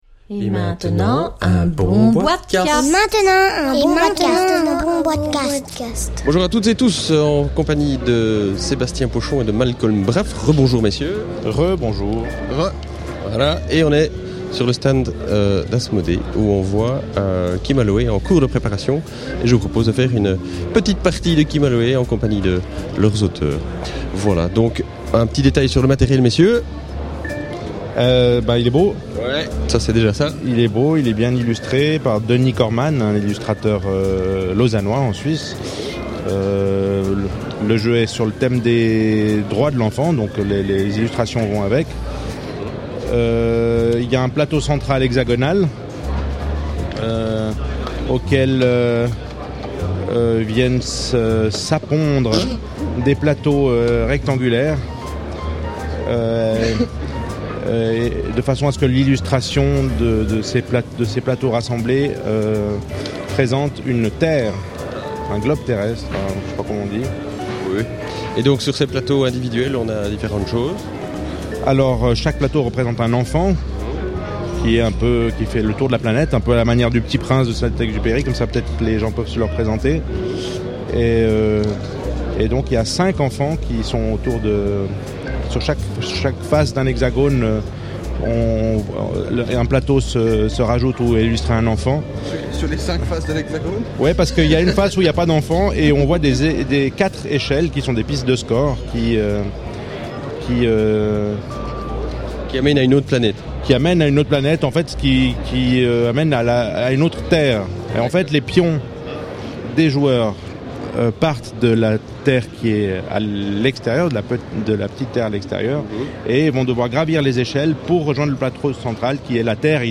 ( enregistré lors du Nuremberg SpielenwarenMesse 2009 )